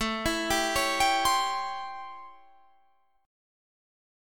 A7#9 chord